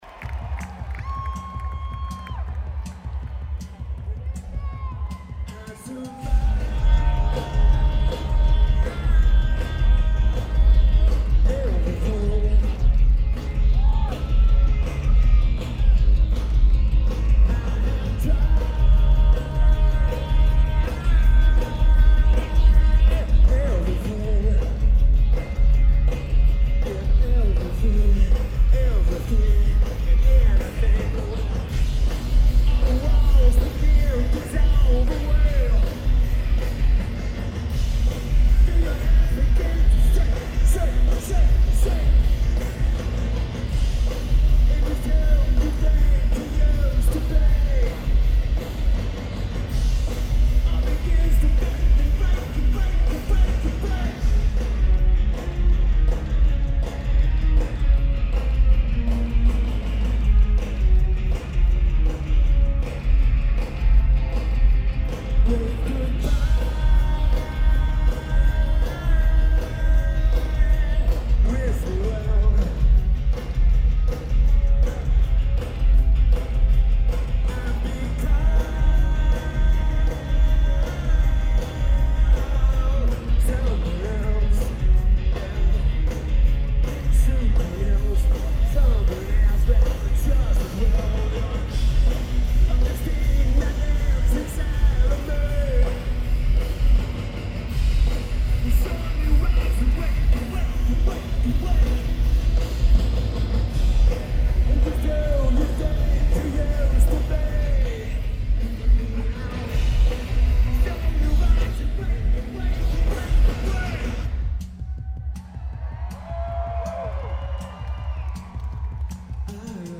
Greek Theatre
Lineage: Audio - AUD (SP-CMC-4U + SP-SPSB-10 + Sony PCM-A10)
Very good tape.
Splicing the left channel in to the right.